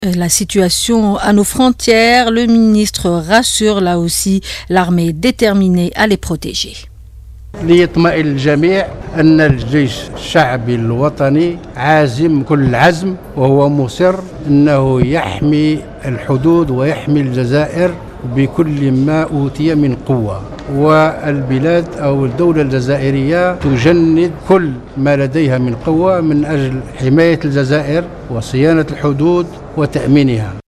Tayeb Belaiz , à propos de la situation à Ghardaïa, au micro de la Chaine 3 Tayeb Belaiz, évoque la protection des frontières algériennes, au micro de la Chaine 3 Tayeb Belaiz , à propos des rumeurs de grève des agents de la protection civile